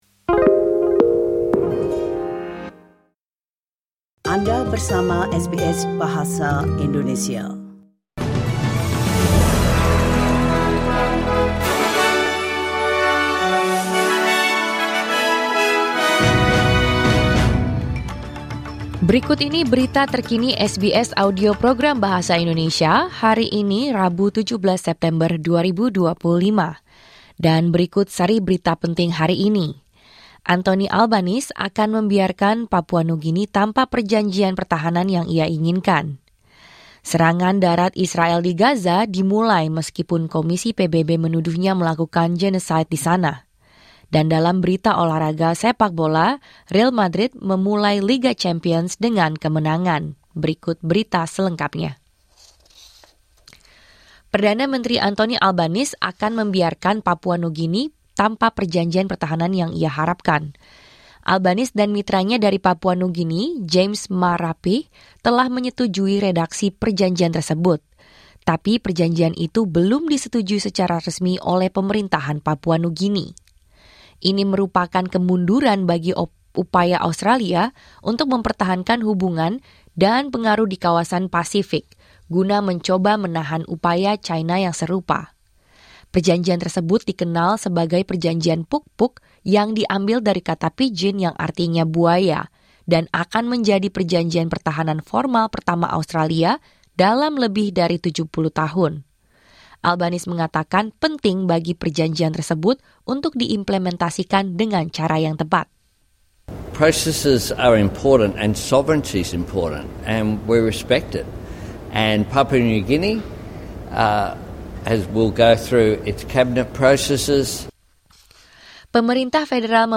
Berita Terkini SBS Audio Program Bahasa Indonesia – 17 September 2025
Berita Terkini SBS Audio Program Bahasa Indonesia, 17 September 2025